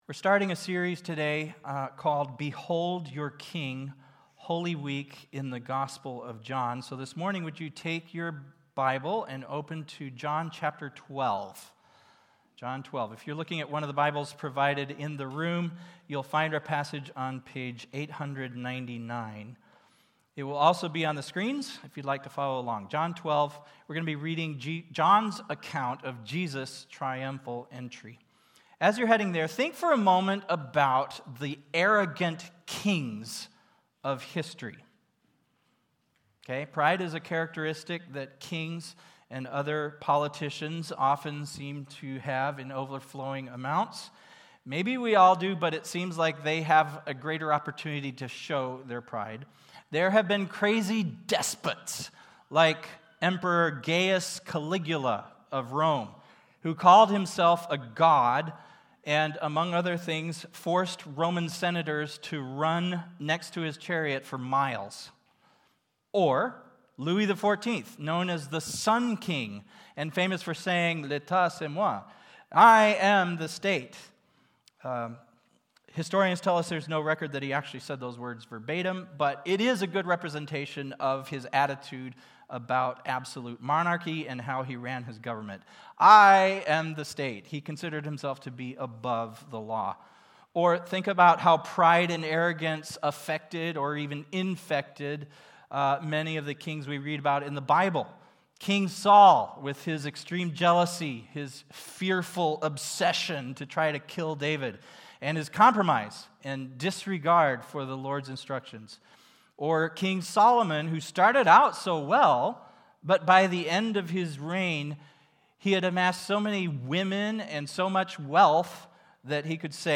March 29, 2026 (Sunday Morning)